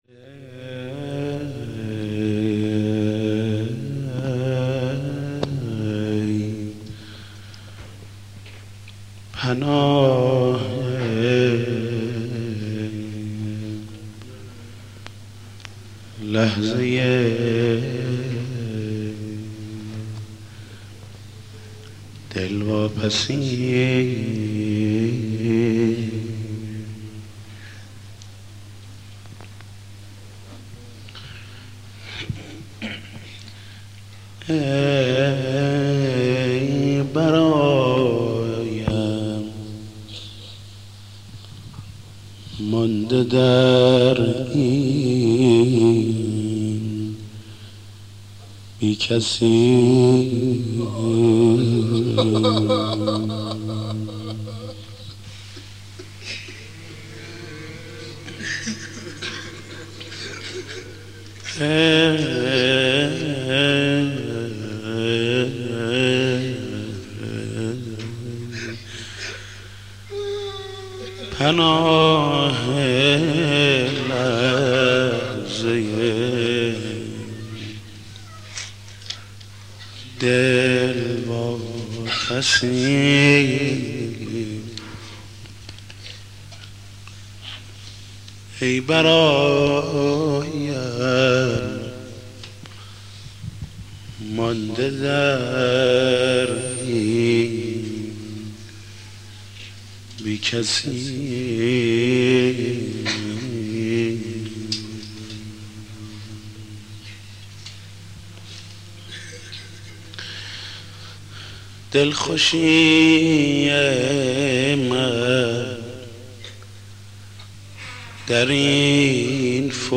مناسبت : دهه دوم محرم
مداح : محمود کریمی